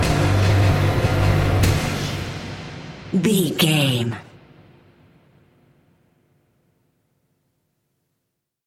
Thriller
Atonal
synthesiser
percussion
ominous
dark
suspense
haunting
tense
creepy